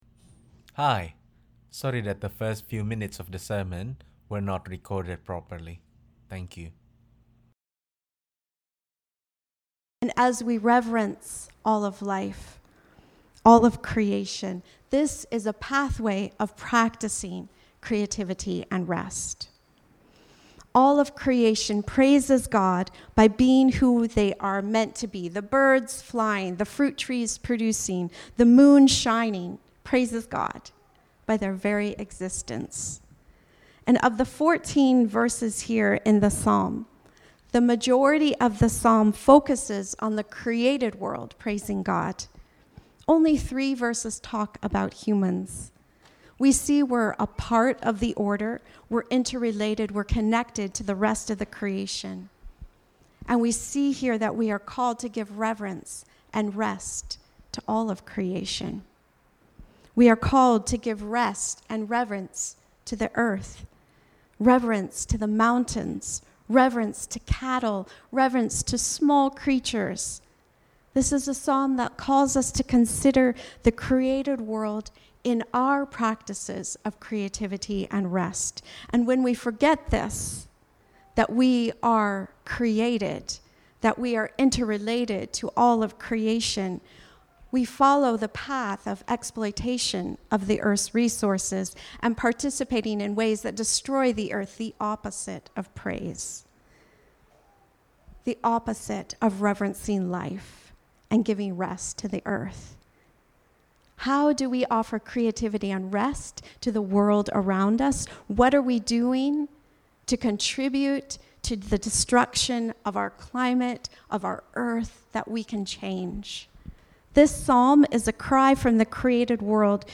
Sermons preached at Grandview Church in Vancouver, BC.